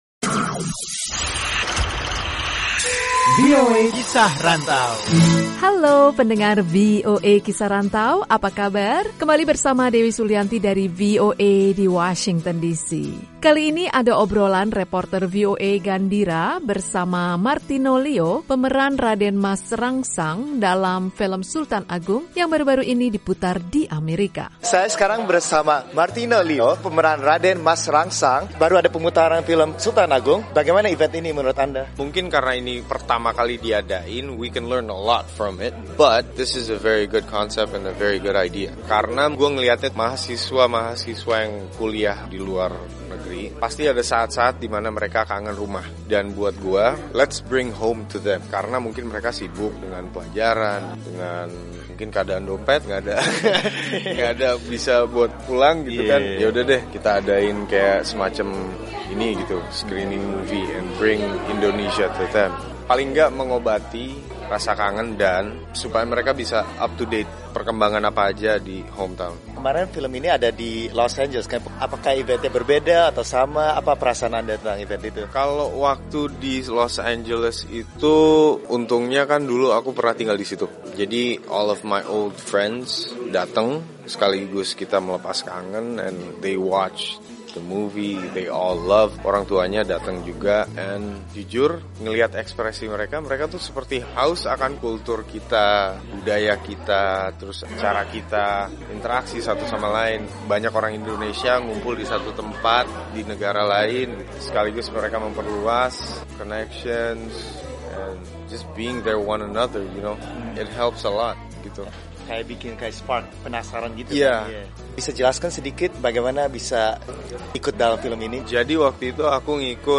Kali ada obrolan bersama aktor Marthino Lio seputar pemutaran film Sultan Agung di Amerika.